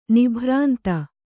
♪ nibhrānta